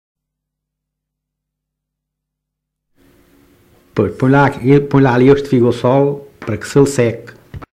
excerto 81 Localidade Alpalhão (Nisa, Portalegre) Assunto As árvores de fruto e os frutos Informante(s